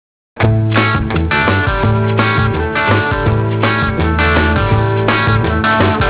イントロが似ています（似てる度95）。